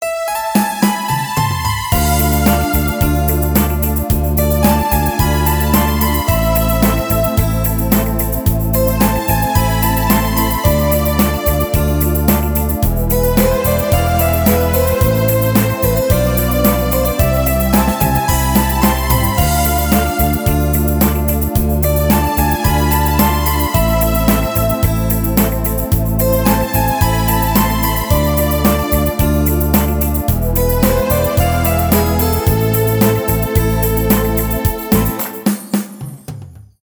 легкие , без слов , инструментальные
поп